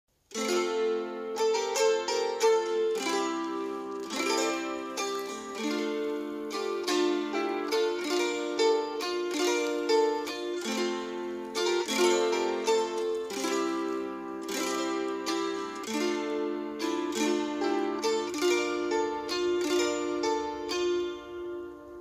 в исполнении парня под аккомпанемент гуслей